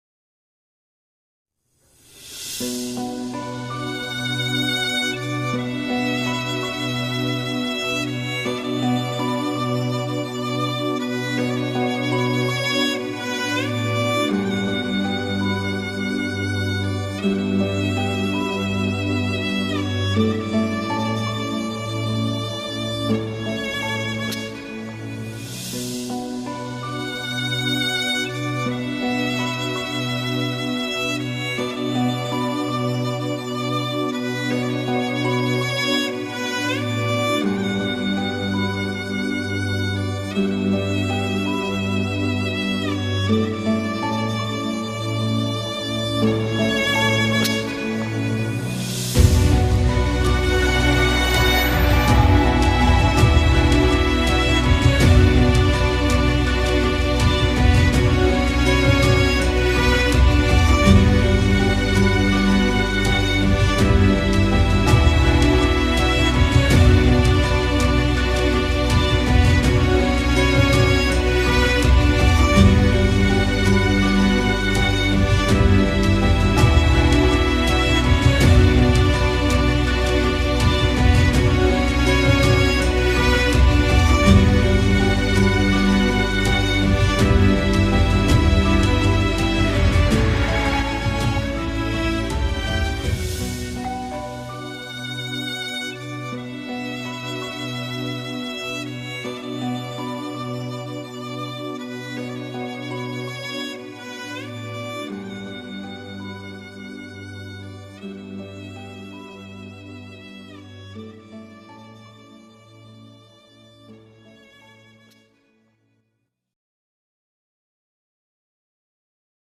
duygusal hüzünlü üzgün fon müziği.